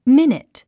発音 mínit ミニッツ